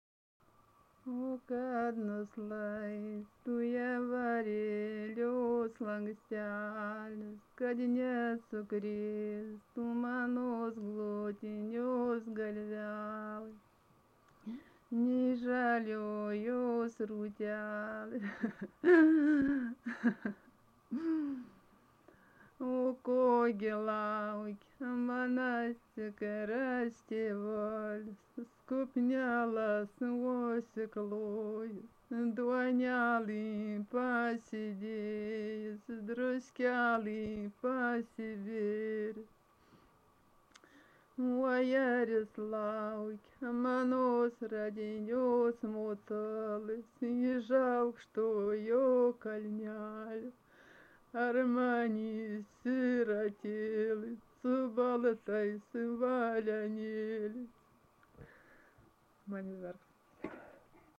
Tipas daina Erdvinė aprėptis Dargužiai (Varėna)
Atlikimo pubūdis vokalinis